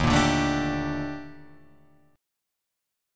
EbM#11 chord